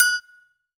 YAGOGO HI.wav